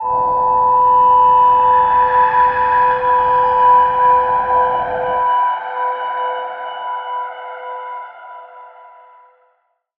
G_Crystal-B6-mf.wav